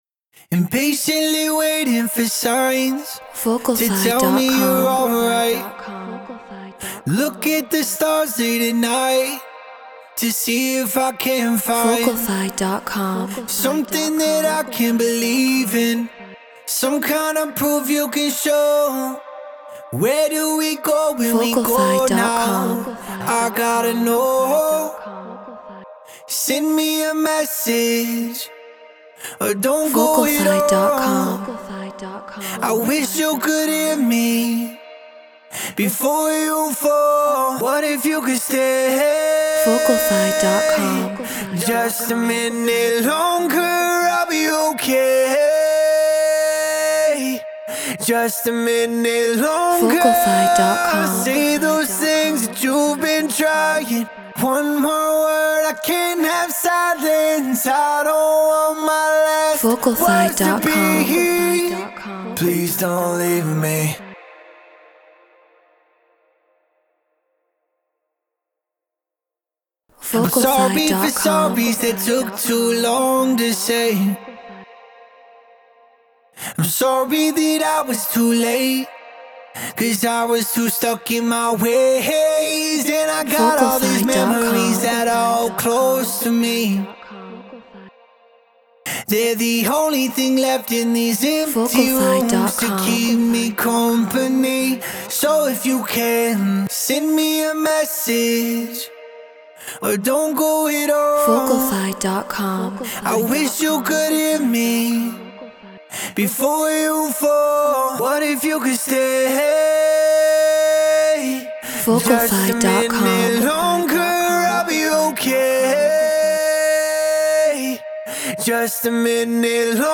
Pop 150 BPM D#maj